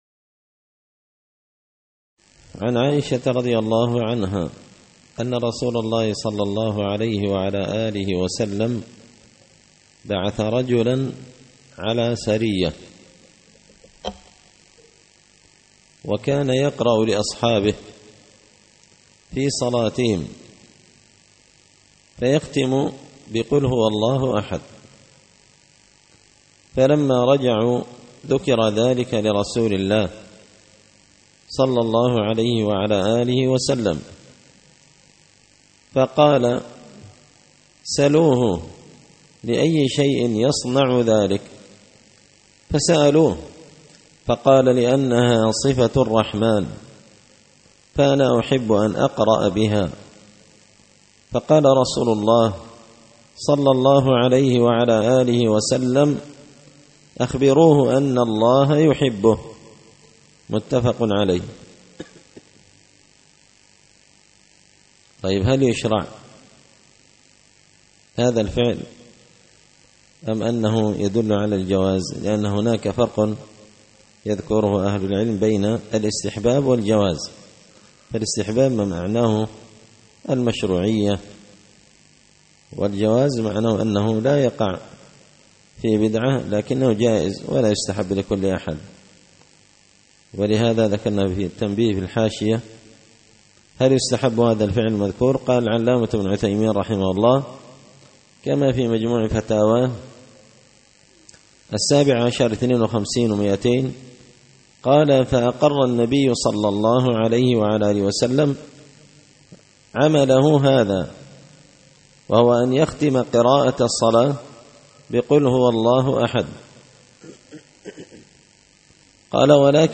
الأحاديث الحسان فيما صح من فضائل سور القرآن ـ الدرس الثامن والخمسون